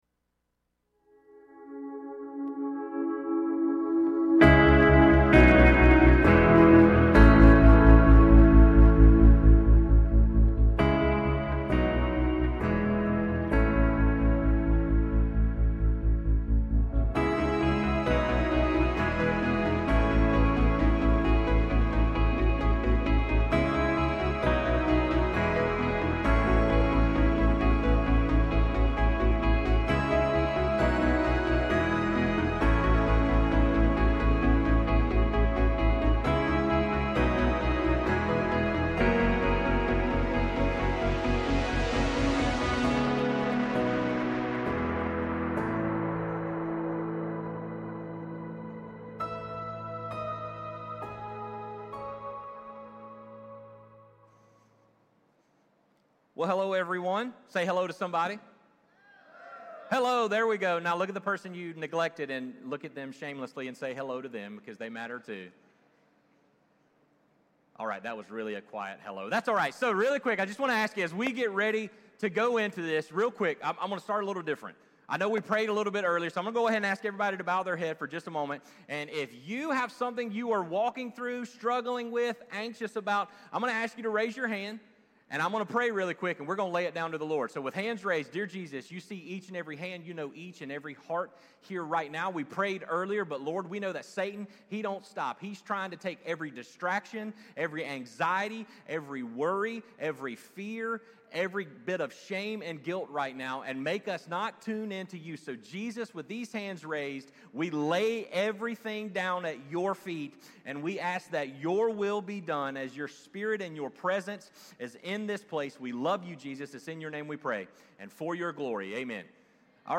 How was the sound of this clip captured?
Current Sunday Worship Experience